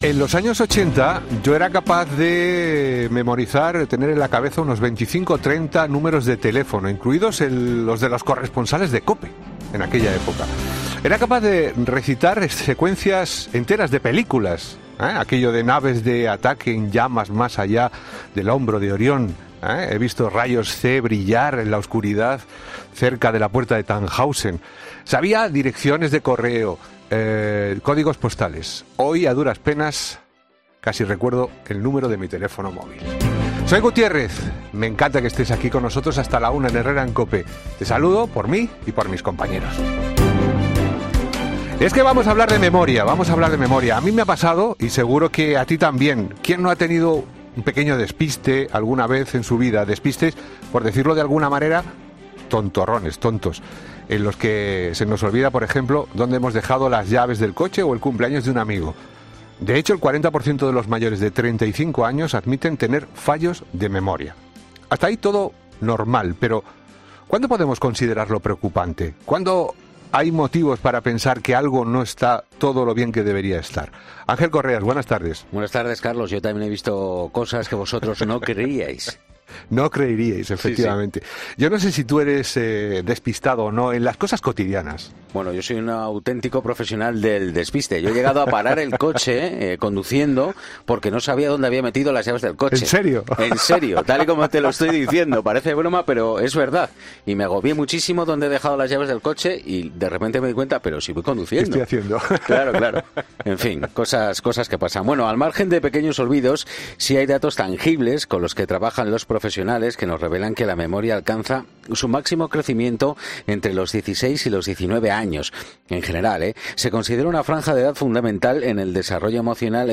Los expertos explican en "Herrera en COPE" el funcionamiento y los problemas de memoria